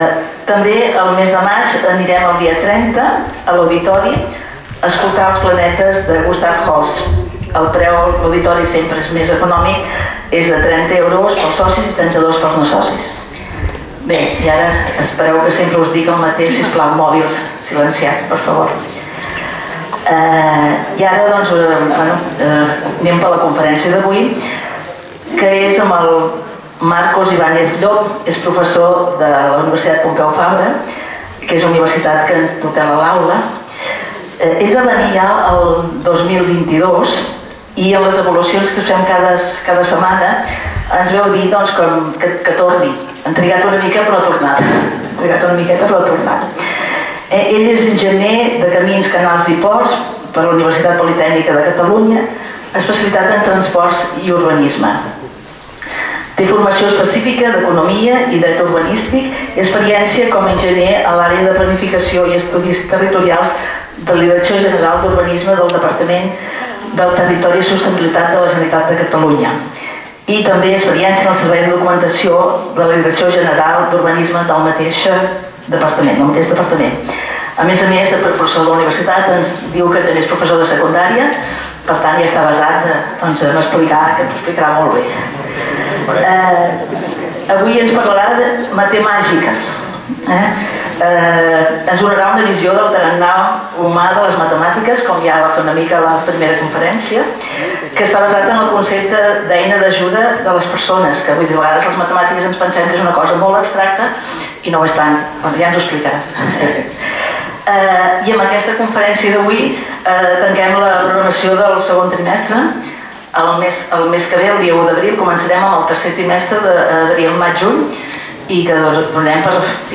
Lloc: Sala d'actes del Col.legi La Presentació
Conferències